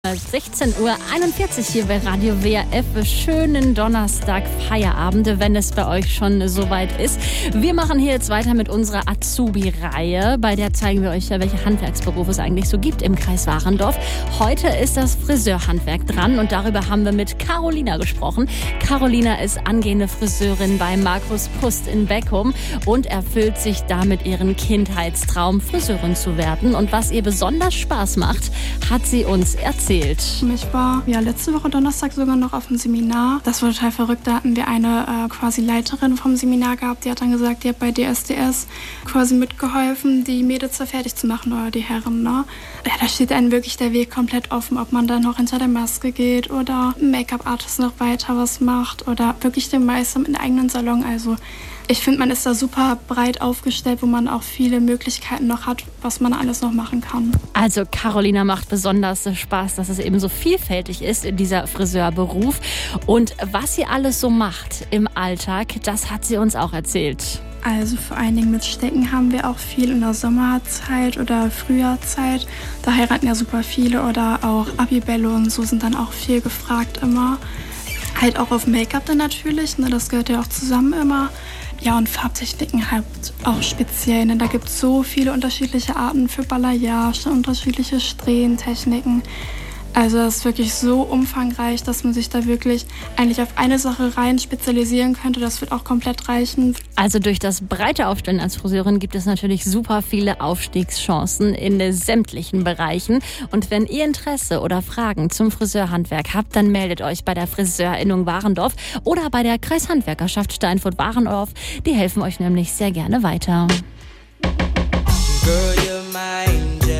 Zusammen mit Radio WAF lassen wir die Azubis zu Wort kommen: Zwei Wochen lang erzählen Auszubildende im Programm des Lokalsenders, warum die Ausbildung im Handwerk für sie genau der richtige Start ins Berufsleben ist.
Mit freundlicher Genehmigung von Radio WAF können wir die Mitschnitte der Beiträge hier nach der Ausstrahlung zum Nachhören veröffentlichen.